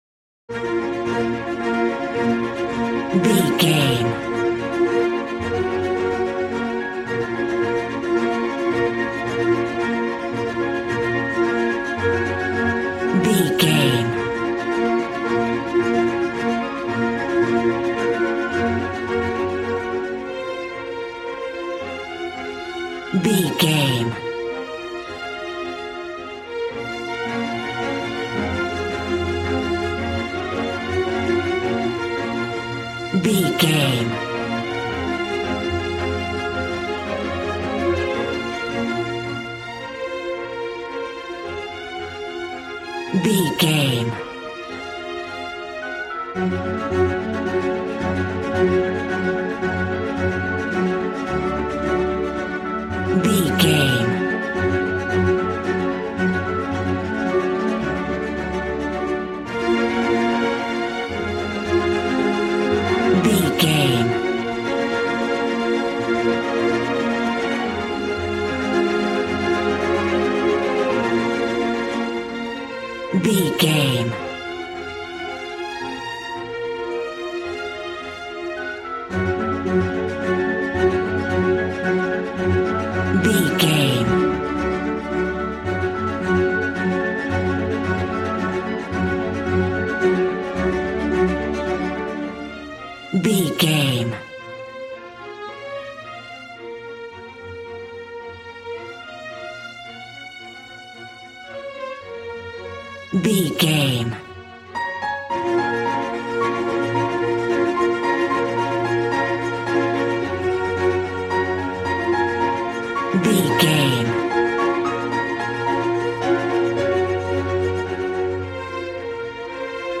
Regal and romantic, a classy piece of classical music.
Aeolian/Minor
B♭
regal
cello
violin
strings